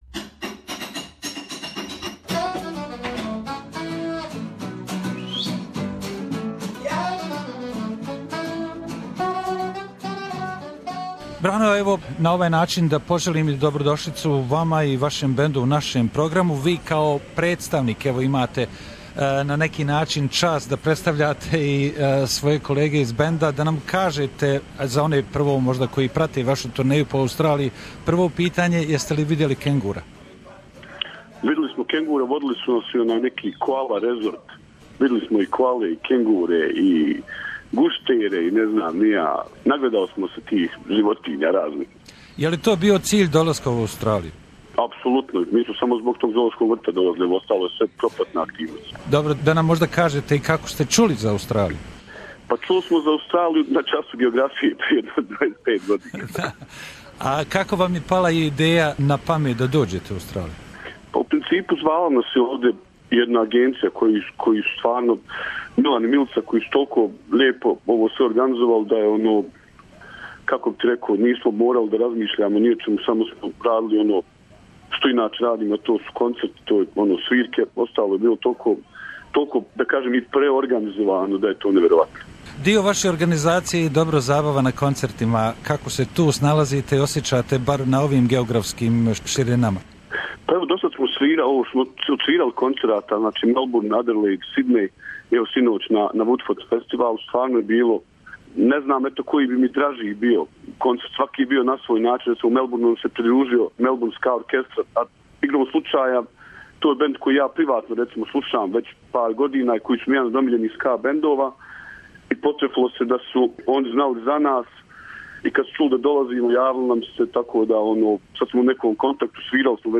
Interview Dubioza Kolektiv is playing at the Woodford Folk Festival on Thursday Dec 29